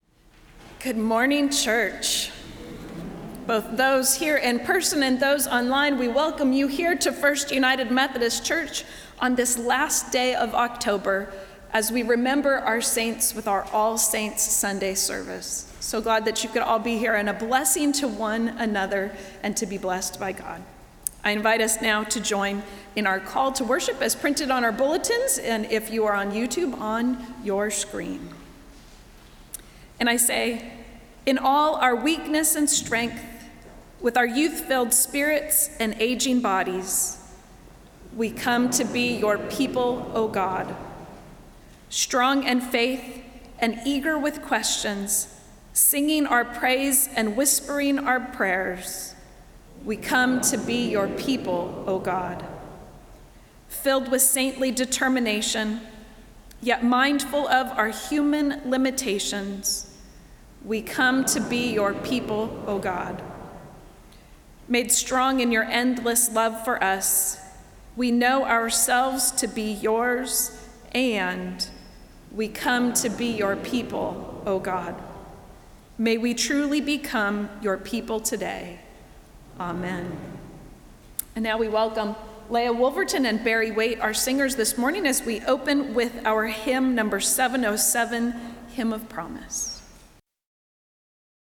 Service of Worship
Welcome and Opening Prayer